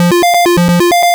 retro_synth_beeps_10.wav